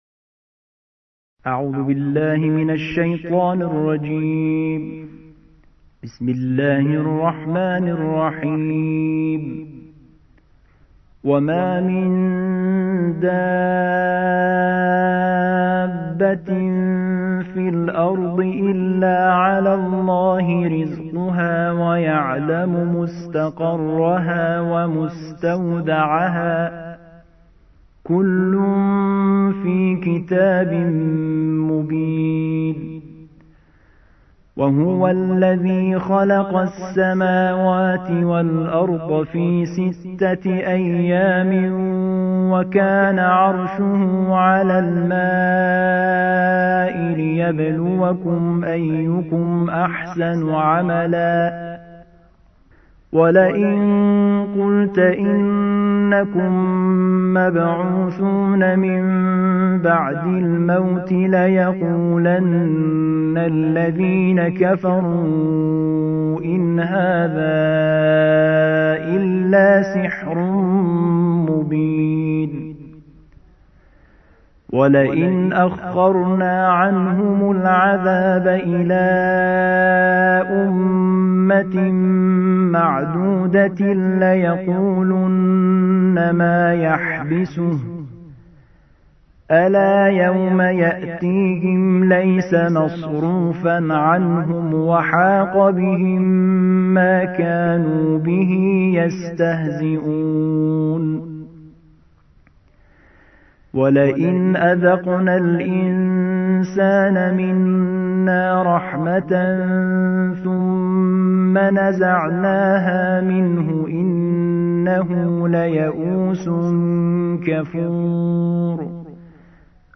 الجزء الثاني عشر / القارئ